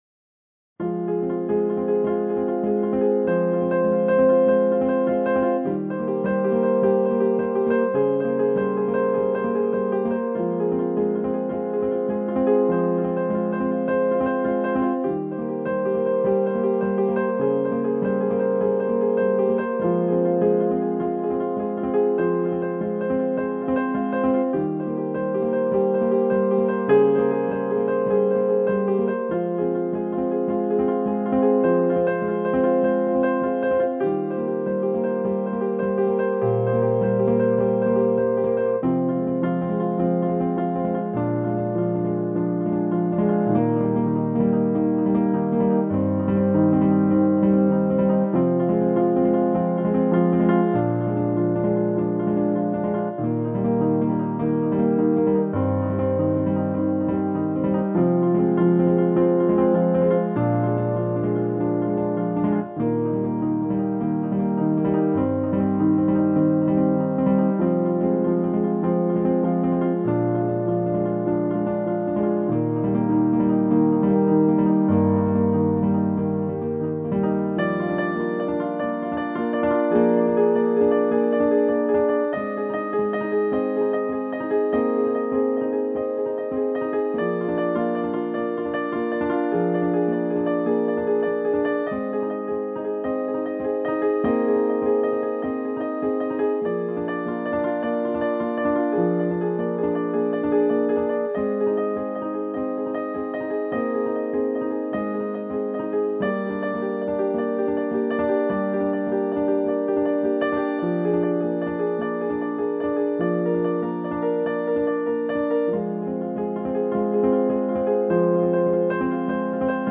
spelen op neppiano